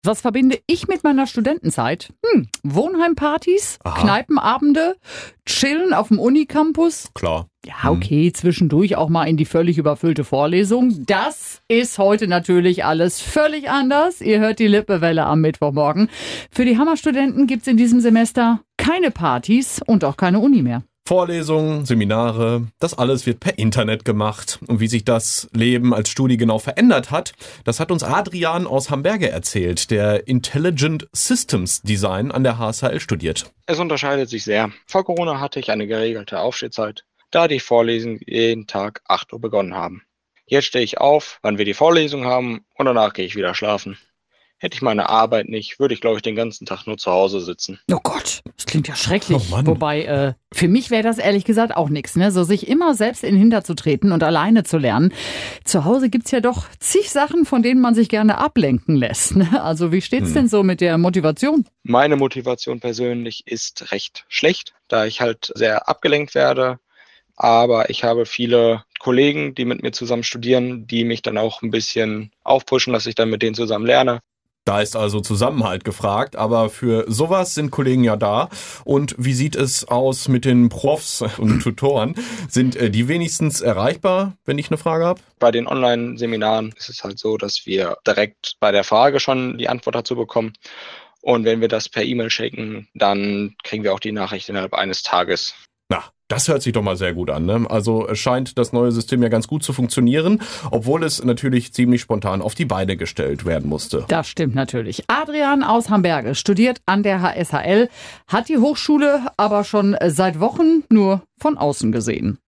Ein Hammer Student erzählt, wie es läuft.